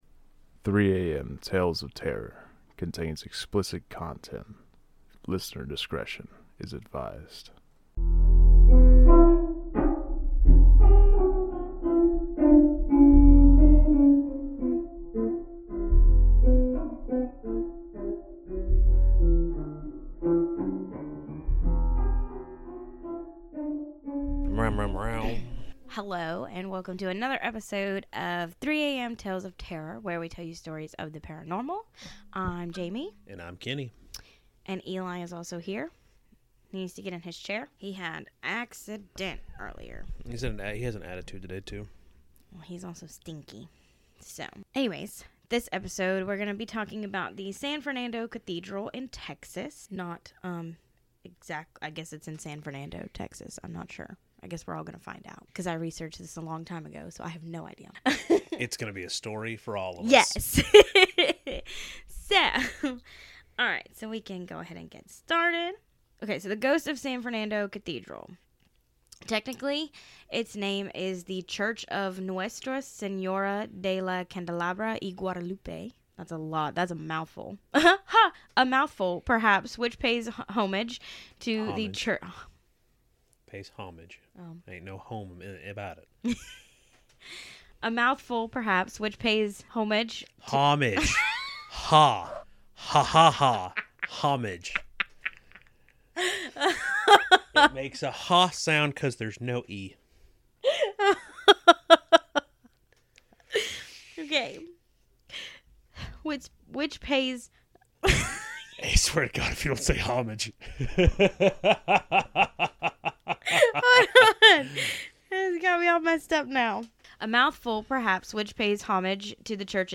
We are a husband and wife duo who love everything there is to love about horror. We will tell stories ranging from paranormal activity, hauntings, ghost sightings, cryptids, and lore of all sorts.